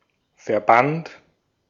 Ääntäminen
Vaihtoehtoiset kirjoitusmuodot organization Synonyymit top brass Ääntäminen US : IPA : /ˌɔɹ.ɡə.nɪ.ˈzeɪ.ʃən/ UK : IPA : /ˈɔː(ɹ).ɡən.aɪˌzeɪ.ʃən/ Tuntematon aksentti: IPA : /ˌɔː.ɡə.naɪ.ˈzeɪ.ʃən/ IPA : /ˌɔː.gə.naɪˈzeɪ.ʃən/